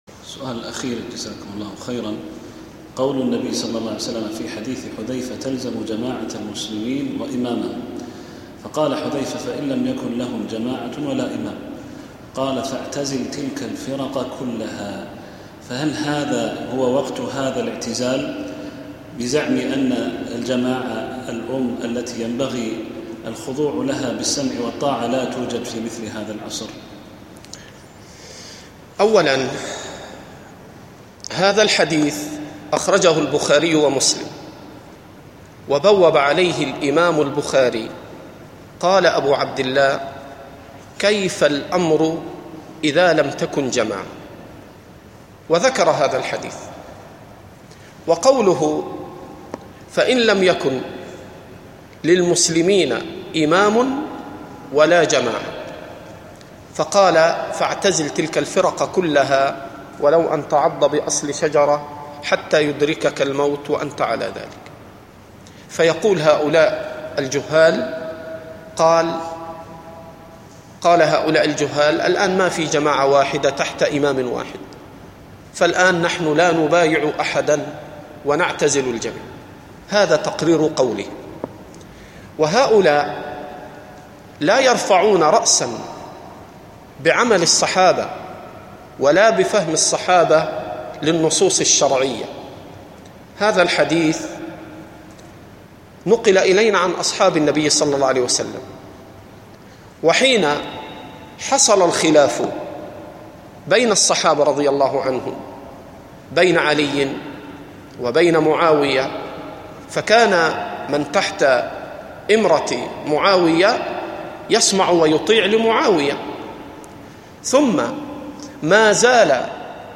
Format: MP3 Mono 44kHz 64Kbps (CBR)